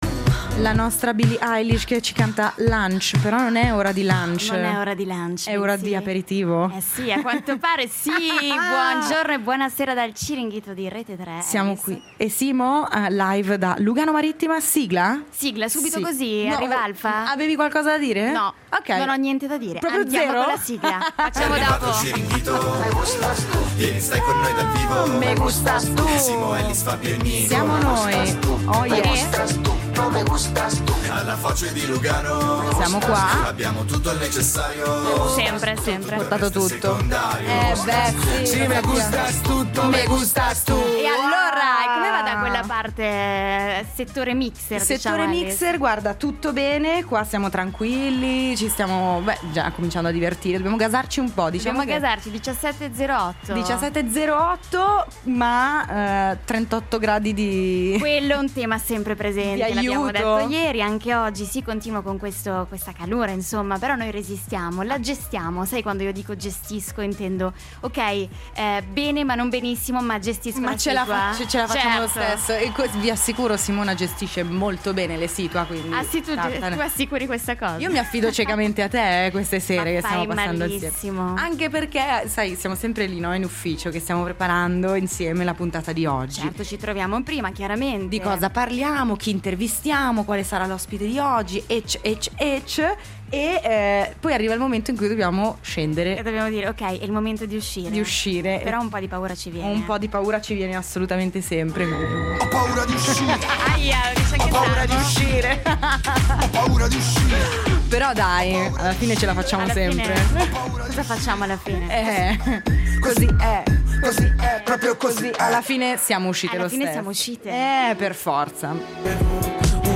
Ospite di questa puntata: Sunset’99 band rock della Svizzera Italiana.